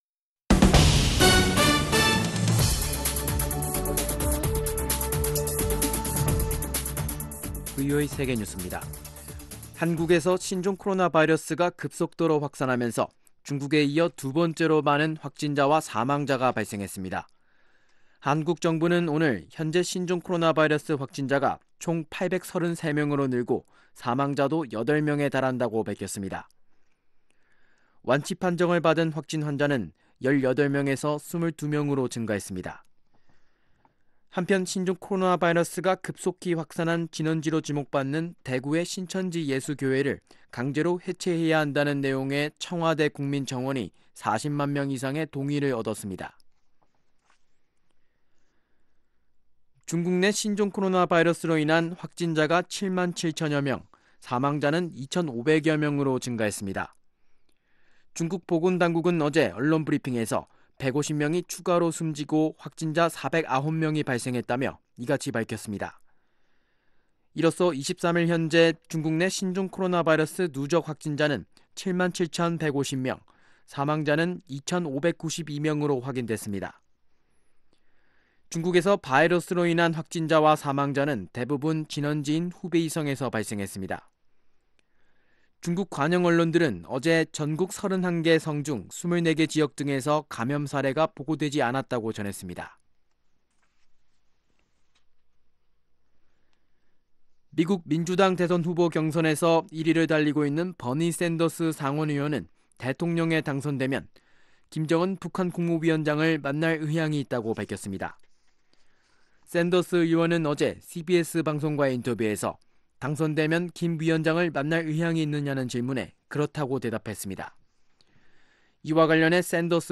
VOA 한국어 간판 뉴스 프로그램 '뉴스 투데이', 2019년 2월 24일 2부 방송입니다. 신종 코로나바이러스가 한국 전역에서 급속도로 확산해 한국 정부가 감염병 위기 경보를 최고 단계로 격상했습니다. 미국과 북한의 베트남 하노이 정상회담 1년을 맞아 준비한 VOA의 기획 보도, 오늘은 그 첫번째 순서로 미-북 협상 장기 교착 상태의 시발점을 짚어봅니다.